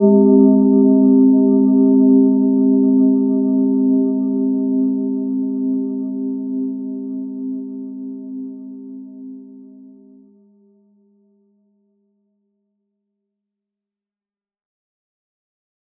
Gentle-Metallic-2-C4-p.wav